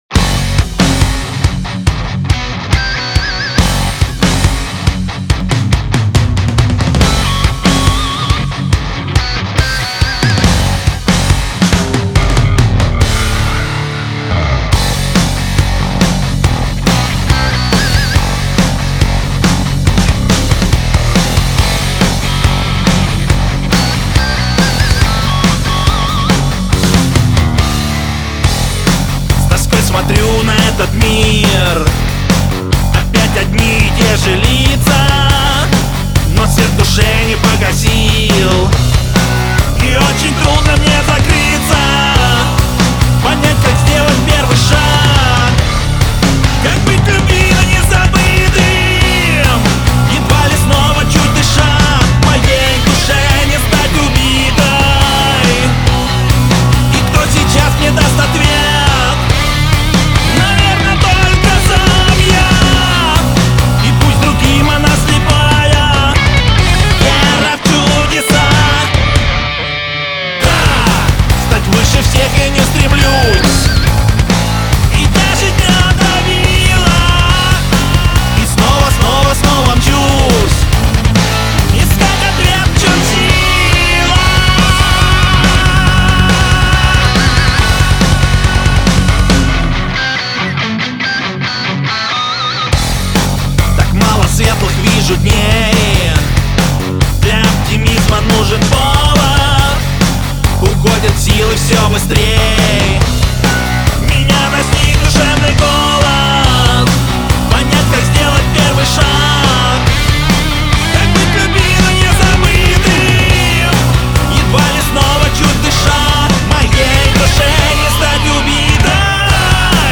"Вера в ..." (Metal Rock)
Я закончил сведение песни записанной у нас в студии.